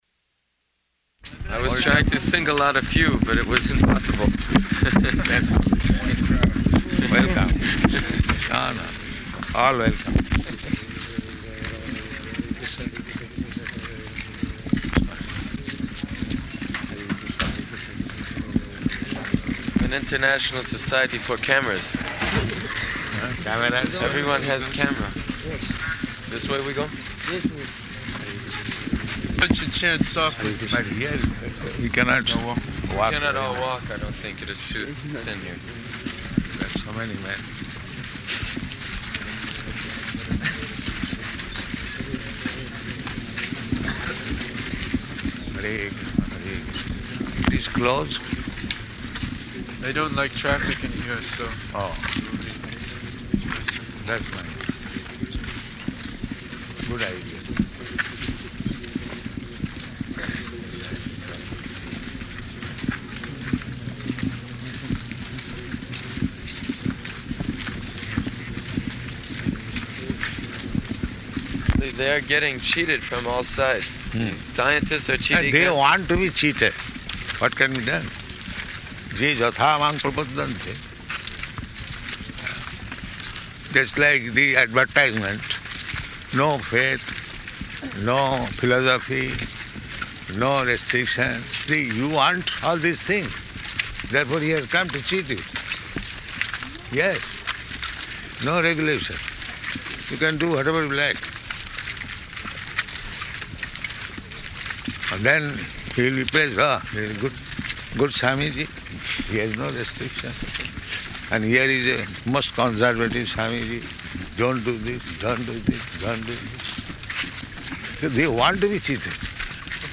-- Type: Walk Dated: August 12th 1975 Location: Paris Audio file